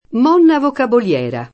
monna [m0nna] (meno com. mona [m0na]) s. f. («madonna») — premesso in antico a nome di donna (a volte con M- maiusc.): E monna Vanna e monna Lagia poi [e mm0nna v#nna e mm0nna l#Ja p0i] (Dante); dovendo Federigo cenar con monna Tessa [dov$ndo feder&go ©en#r kom m0nna t%SSa] (Boccaccio); monna (o mona) Onesta, la «falsa modesta» dei proverbi; monna (o Monna) Lisa, del Giocondo eternata nel ritratto di Leonardo — in locuz. scherz., premesso anche a nome di figura mitica o astratta, oppure a nome com. usato come soprann.